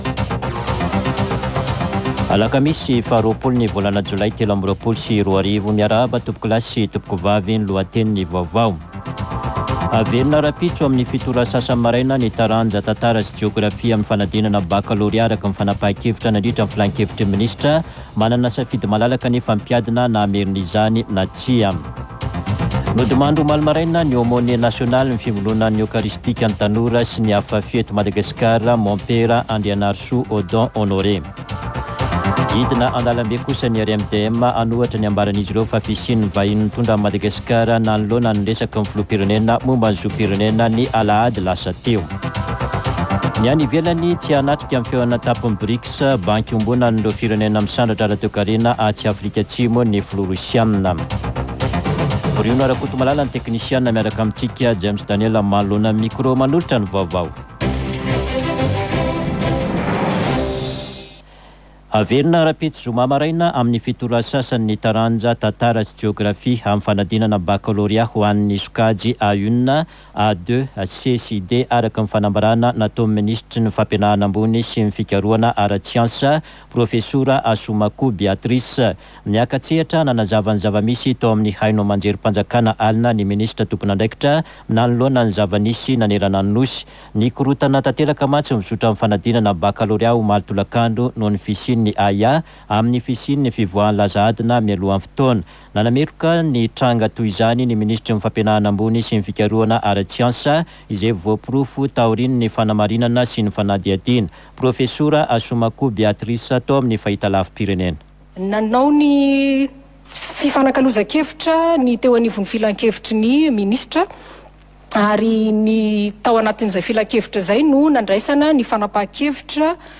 [Vaovao maraina] Alakamisy 20 jolay 2023